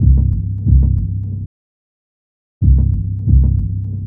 Back Alley Cat (Bass 01).wav